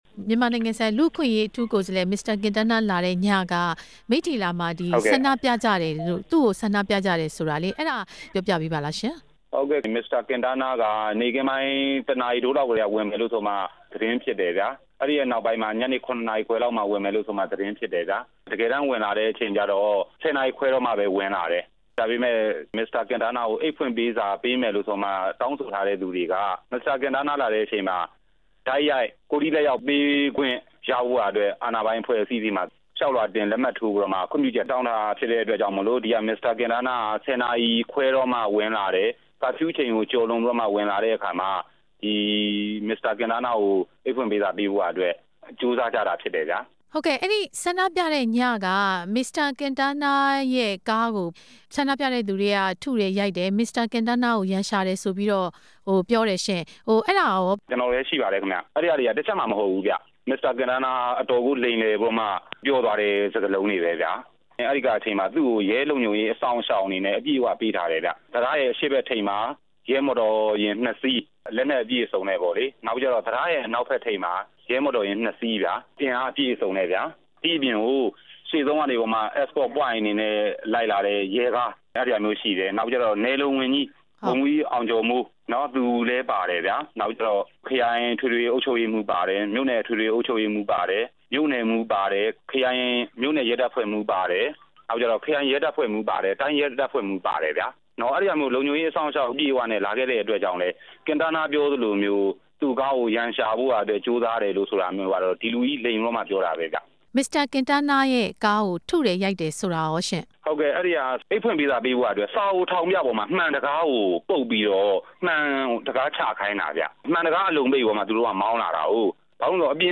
ဆန္ဒပြခဲ့သူတစ်ဦးနဲ့ မေးမြန်းချက်